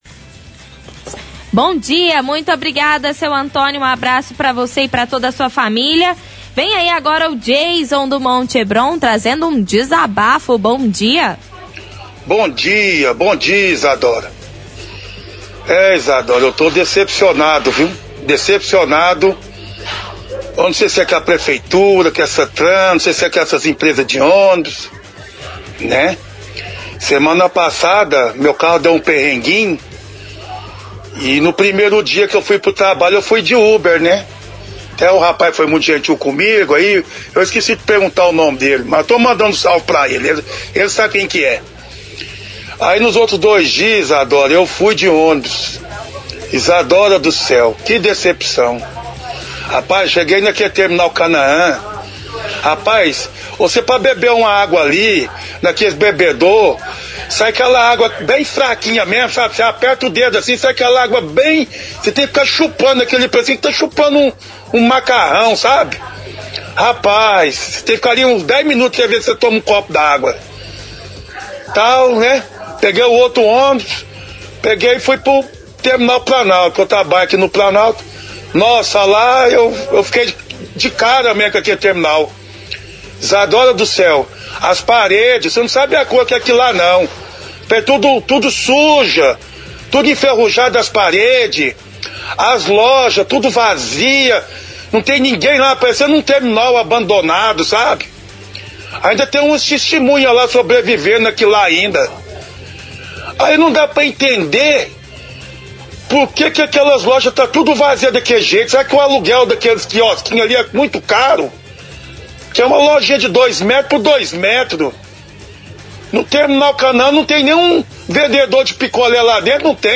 – Ouvinte relata que carro estava na oficina e foi trabalhar pelo transporte público.